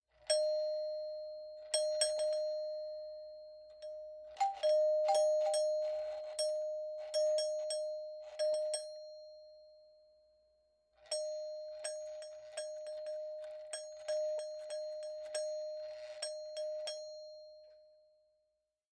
Door Bells; Electronic Eye Type